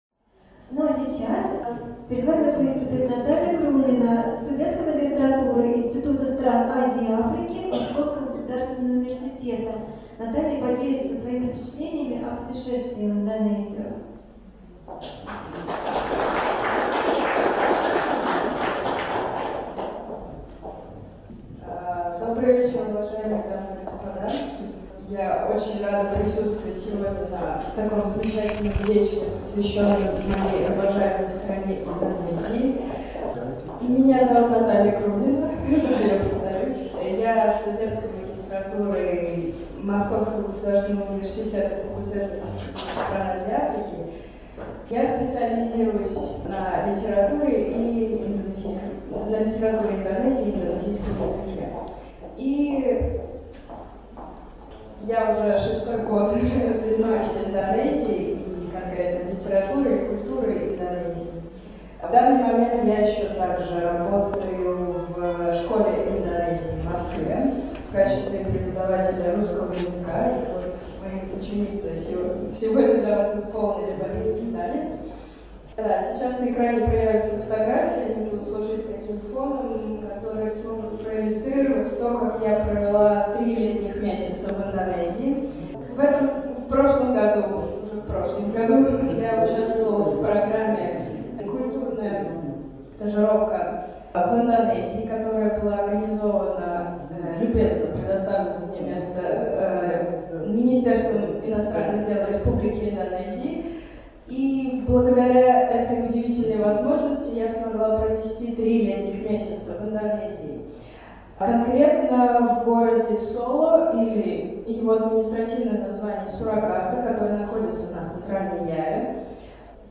Национальный оркестр народов Индонезии – Гамелан | Библиотека и фонотека Воздушного Замка - слушать или скачать mp3
Гамелан – национальный оркестр народов Индонезии, как форма коллективного музицирования возник около трех тысяч лет назад, в классическом виде сложился в 14-15 веках. Основу гамелана составляют ударные инструменты, иногда добавляются флейты и щипковые.